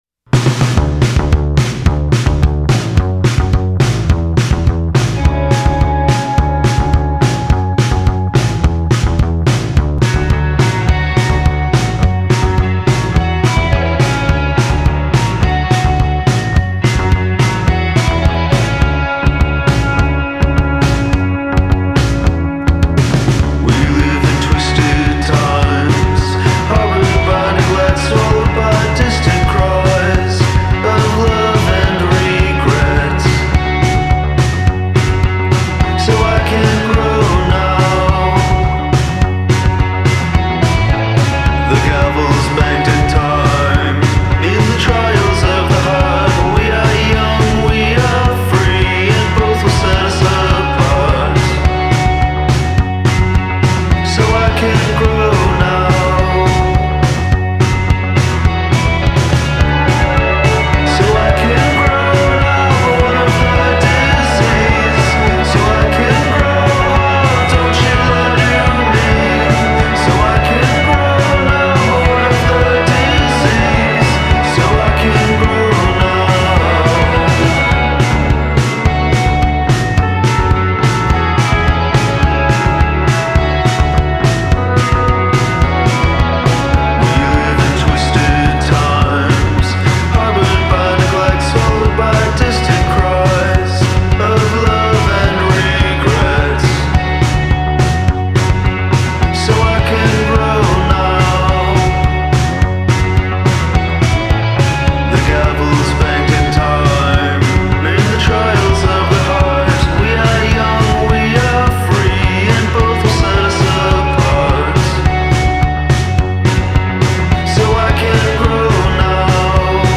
sounds like all harakiri diat bands combined into one